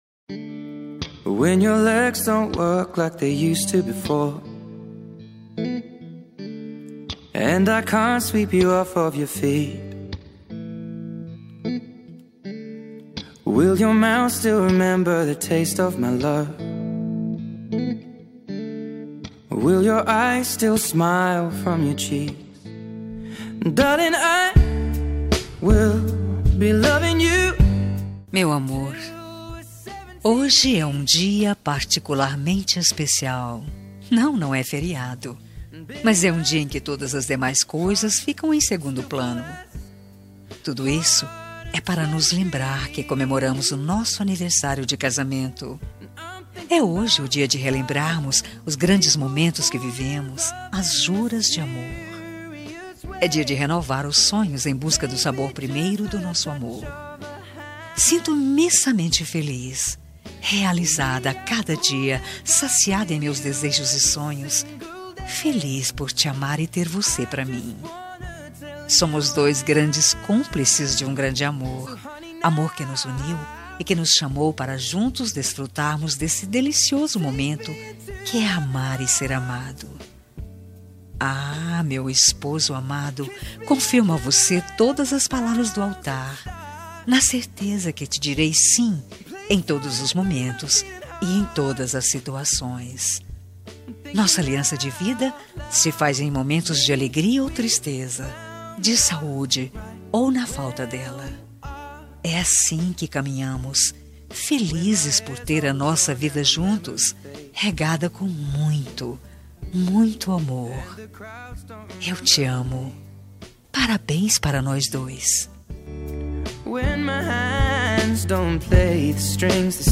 Aniversário de Casamento – Voz Feminina – Cód: 8105 – Linda.